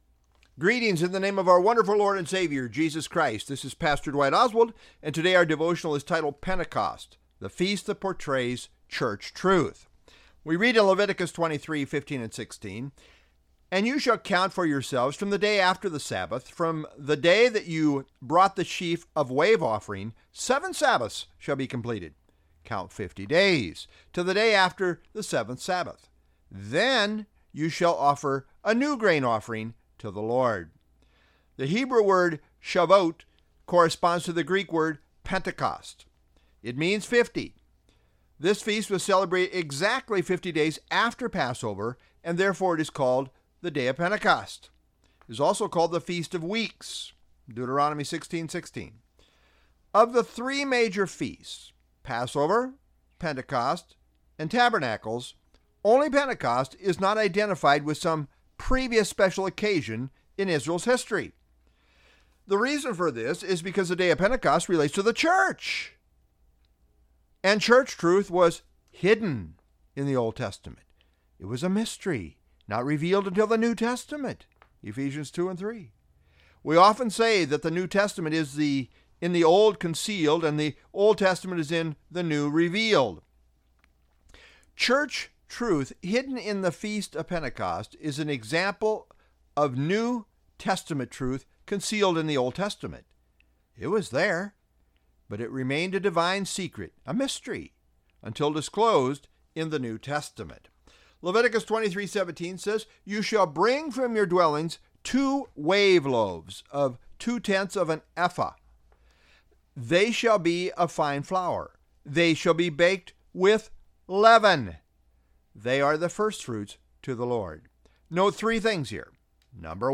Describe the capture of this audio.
February 7, 2024 (Wednesday Evening)